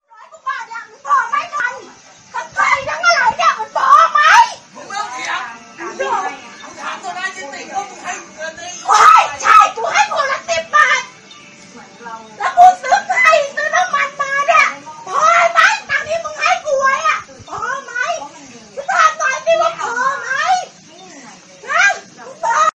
เสียงคนทะเลาะกัน TikTok
tieng-cai-nhau-tiktok-th-www_tiengdong_com.mp3